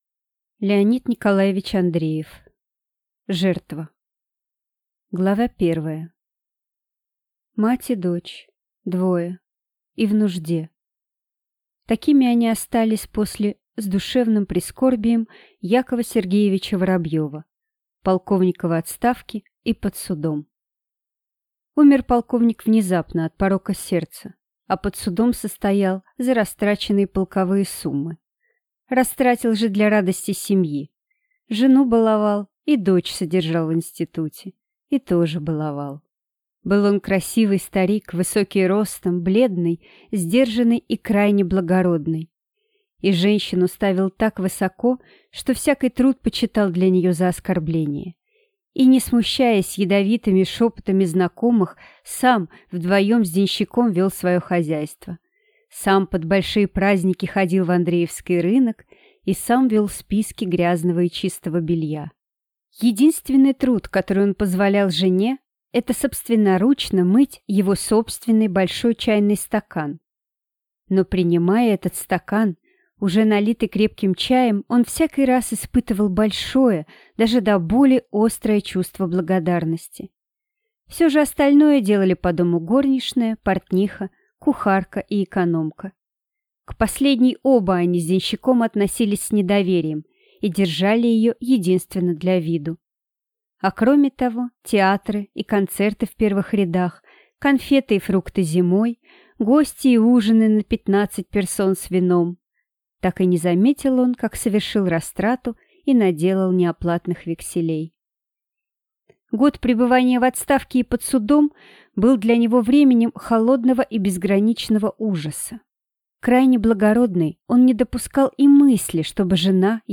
Аудиокнига Жертва | Библиотека аудиокниг